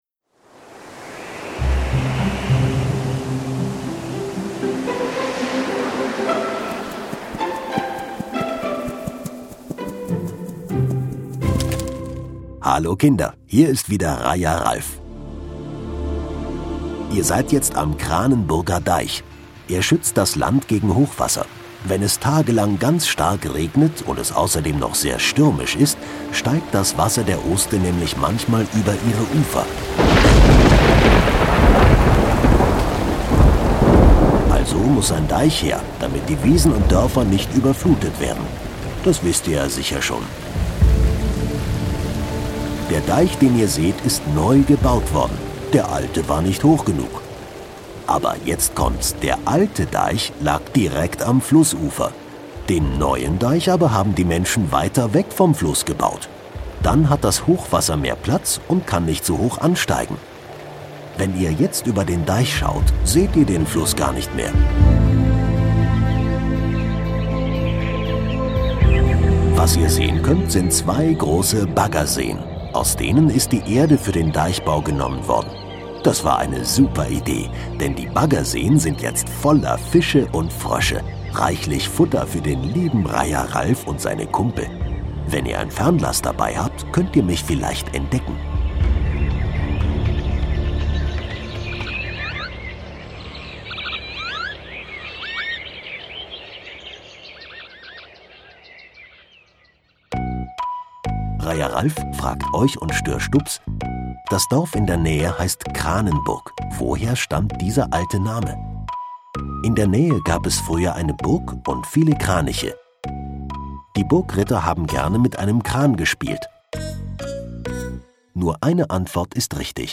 Pütten Kranenburg - Kinder-Audio-Guide Oste-Natur-Navi